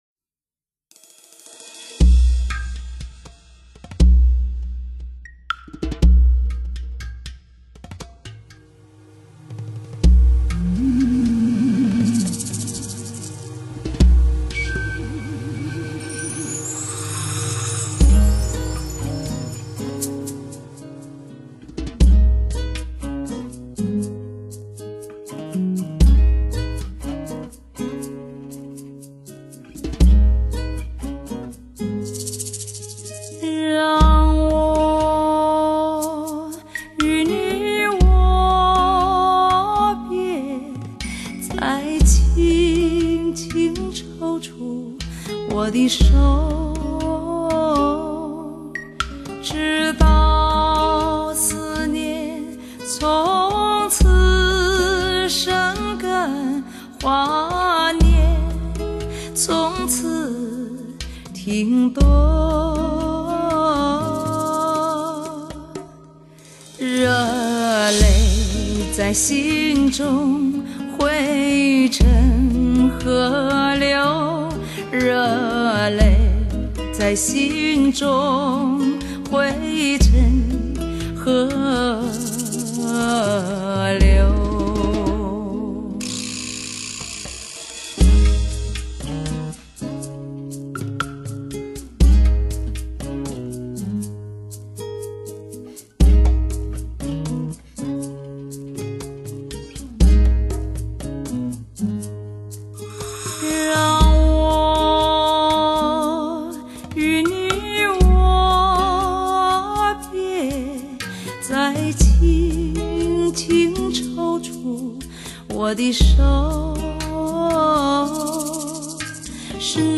国内首张采用STS双声道环绕编码技术的人声发烧天碟